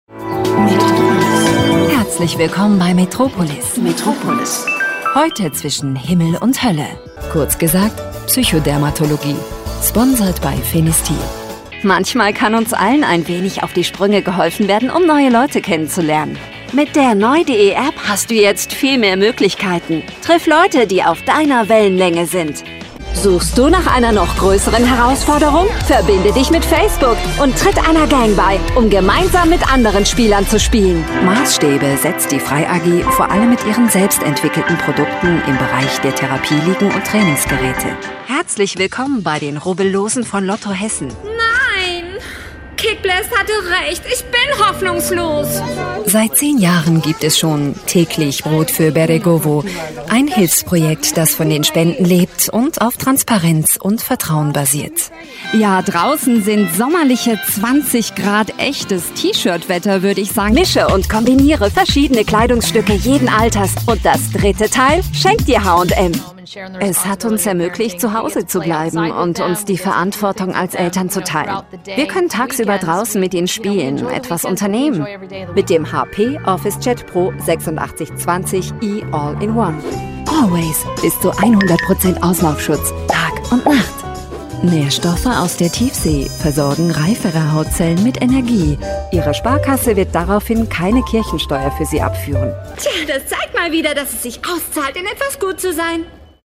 Ausgebildete Sprecherin mit eigenem Studio!
Sprechprobe: Sonstiges (Muttersprache):